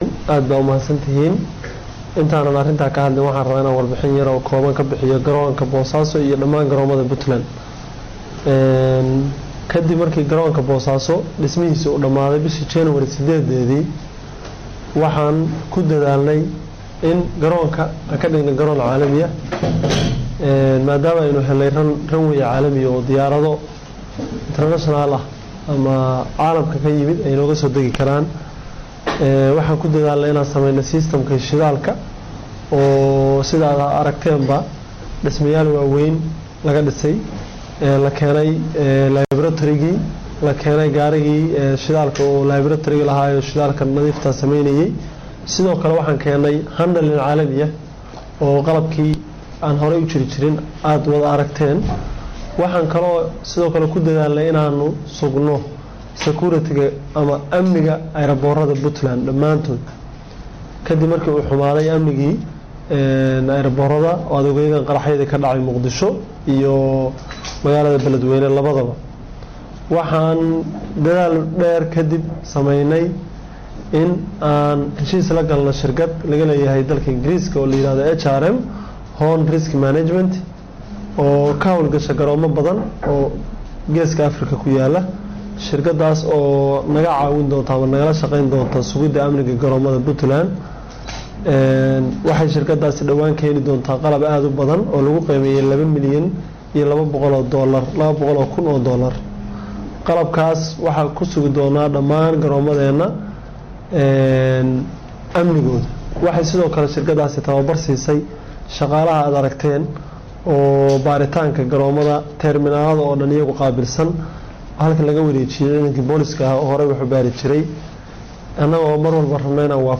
Dhageyso Agaasimaha wasaaradda duulista hawada iyo garoomadda Puntland